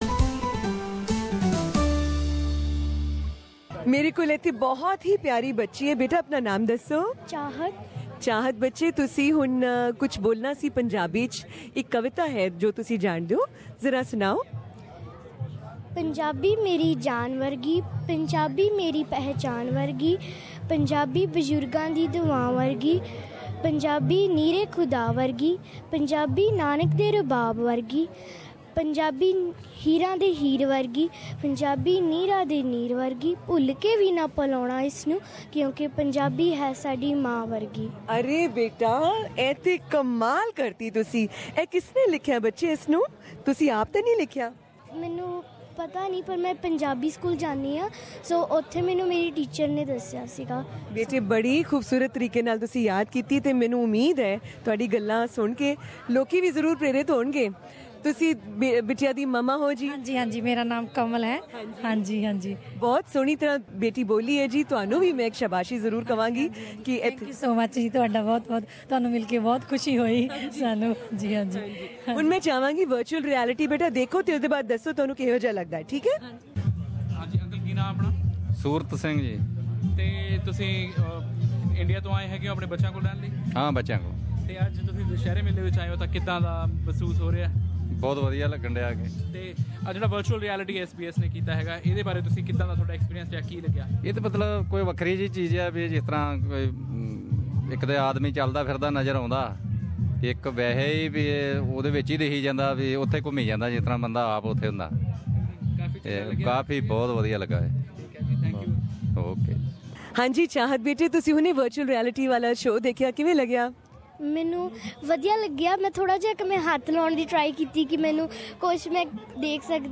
Here we have audio coverage of Dussehra celebrations at Sri Durga Temple, Melbourne, on Sunday Oct 16.